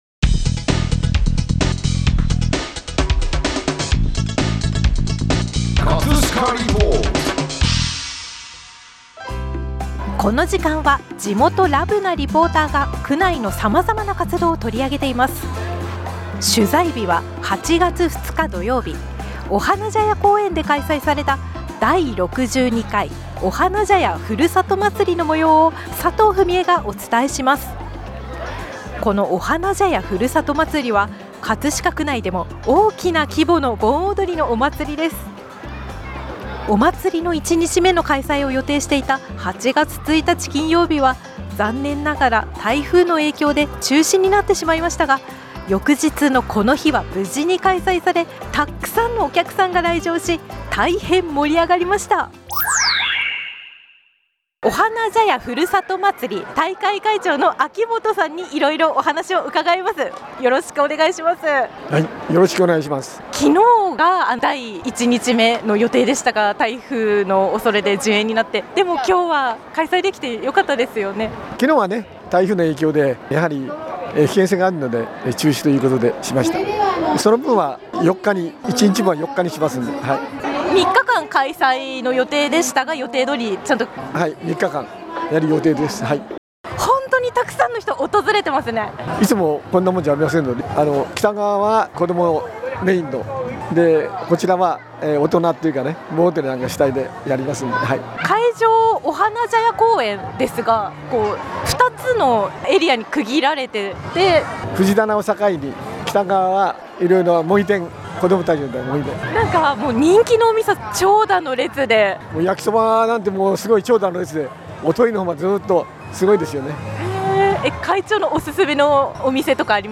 8月2日・3日・4日の3日間にわたり、お花茶屋公園で「第62回お花茶屋ふるさとまつり」が開催されました！
▼リポート音声